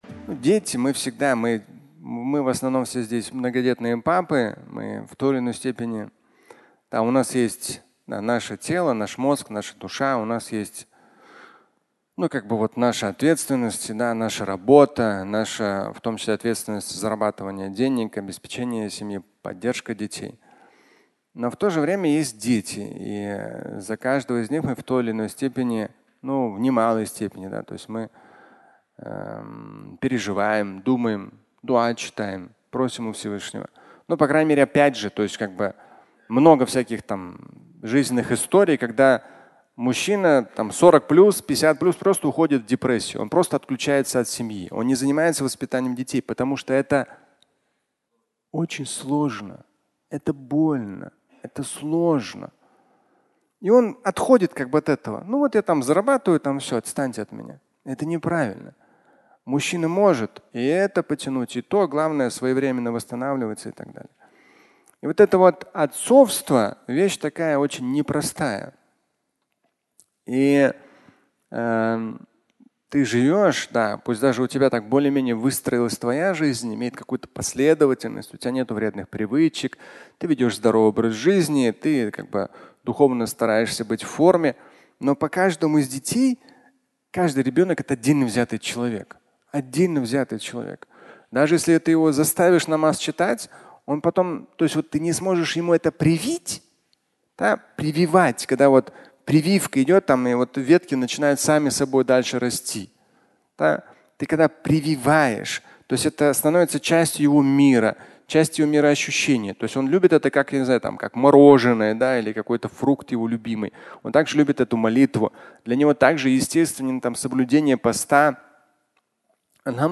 Родитель и ребенок (аудиолекция)